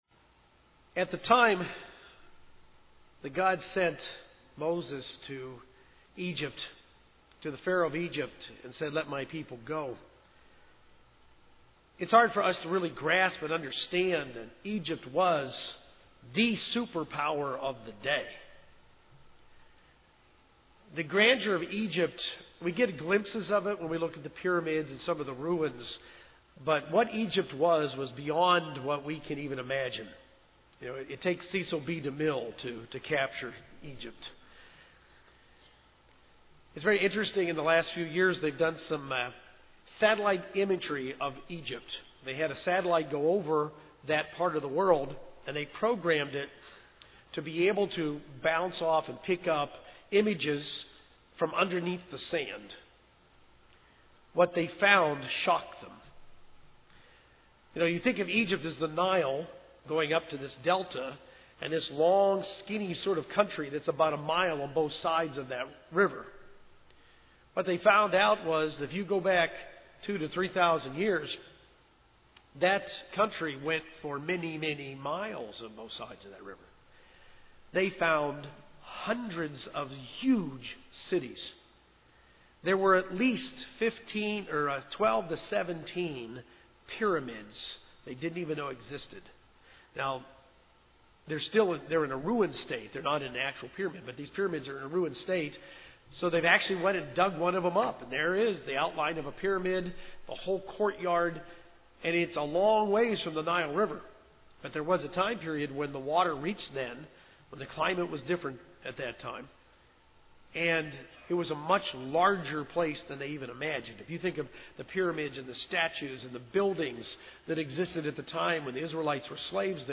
1st Day of Unleavened Bread 2013. Why does it say that God hardened Pharaoh's heart? Does he harden people's hearts today?